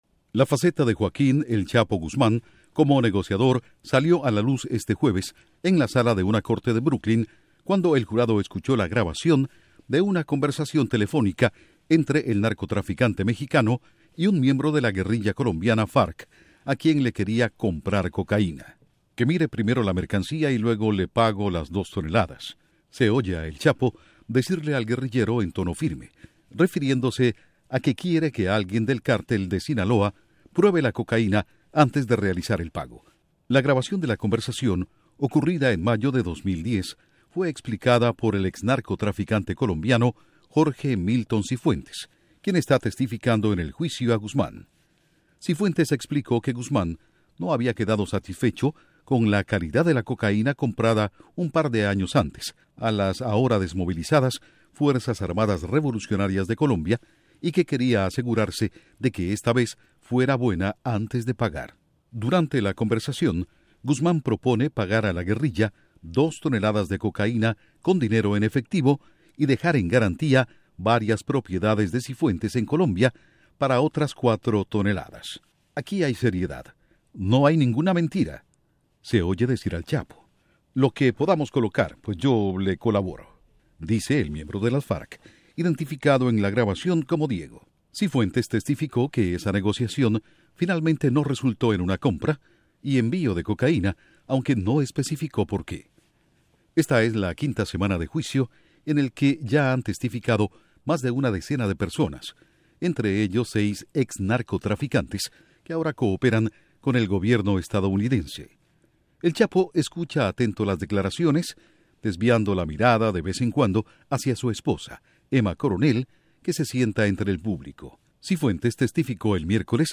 Jurado escucha conversación telefónica de “El Chapo” y un miembro de las FARC de Colombia. Informa desde la Voz de América en Washington